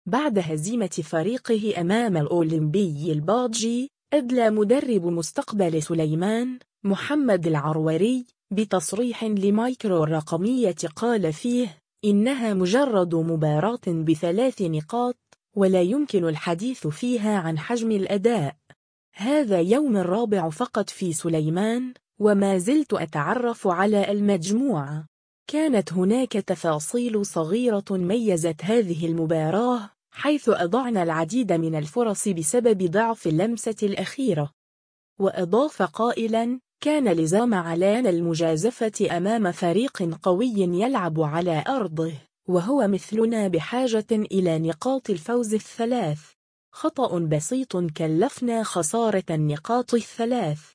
الرابطة المحترفة الأولى: باجة – سليمان، تصريح